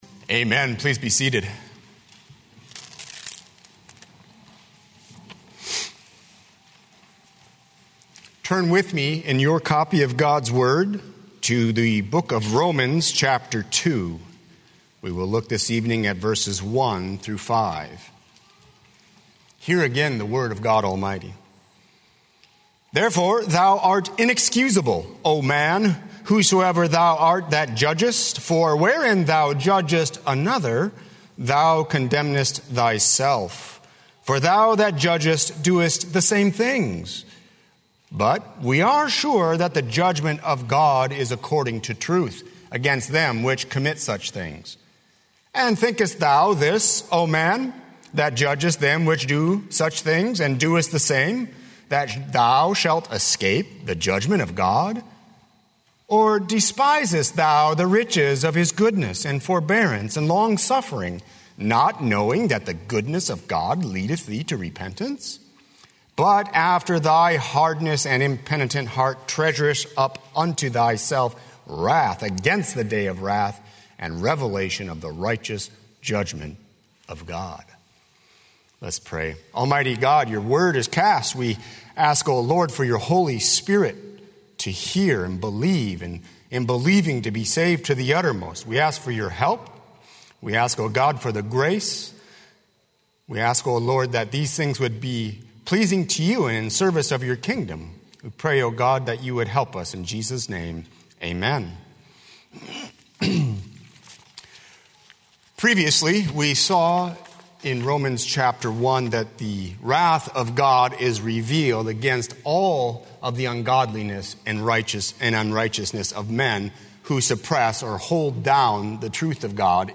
00:00 Download Copy link Sermon Text Romans 2:1–5